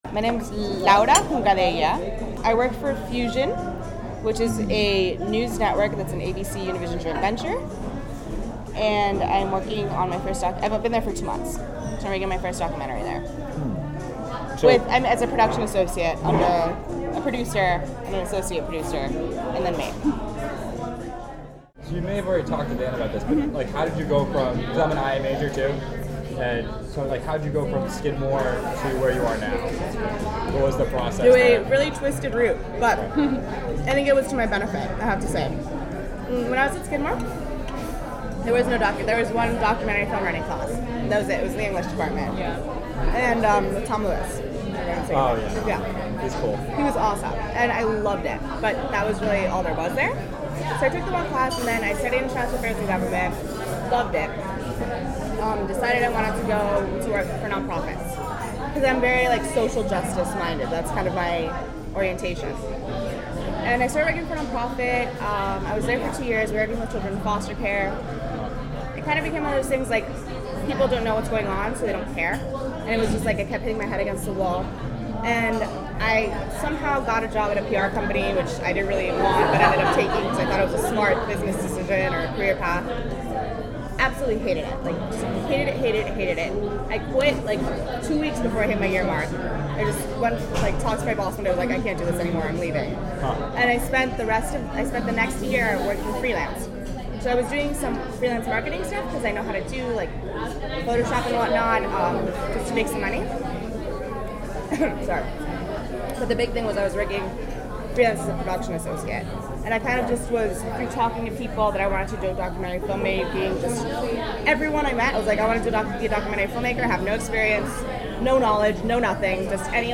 Listen to excerpts from the conversation to hear how a liberal arts program paved the way for her current job with Fusion, a media company working on important stories for the YouTube generation, in which she shares  the story of how she came to work at Fusion and the importance of visual storytelling.